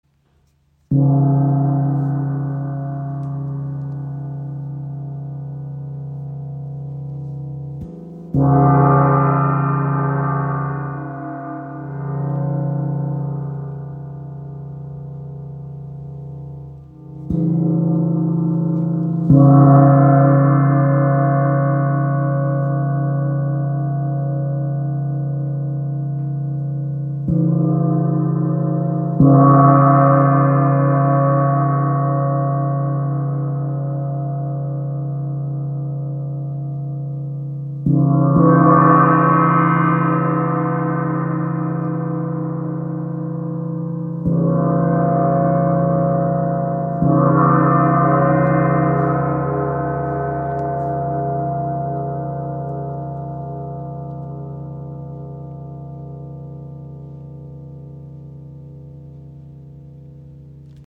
Kleiner Handgong – Leicht im Gewicht, gross im Klang
Universe Gong | Hand Gong | ø 50 cm
Panorama Gong (50 cm, 1,8 kg) – Handgefertigt in Portugal, kompakt und kraftvoll.
Mit einem Gewicht von nur 1,8 kg und einem Durchmesser von 50 cm entfaltet der Panorama Gong trotz seiner kleinen Grösse einen erstaunlich kraftvollen Klang.
• Material: Edelstahl